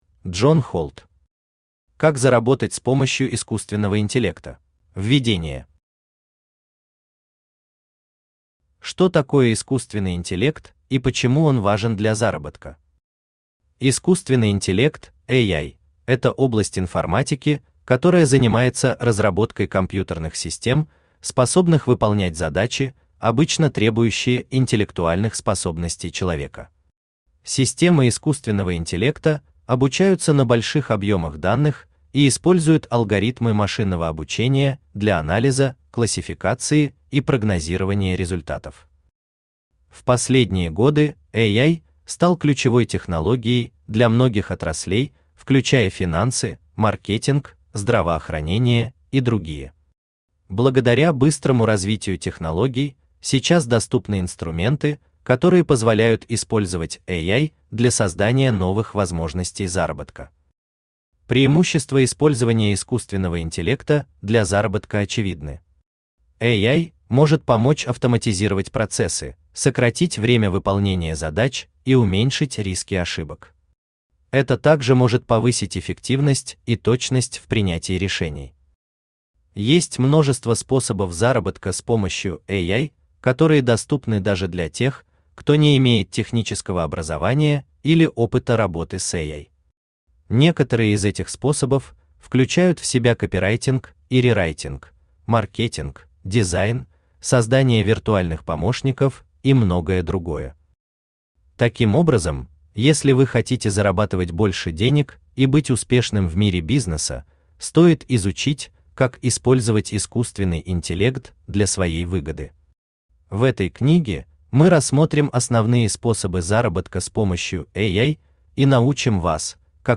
Аудиокнига Как заработать с помощью искусственного интеллекта?
Автор Джон Холд Читает аудиокнигу Авточтец ЛитРес.